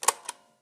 ff up.aiff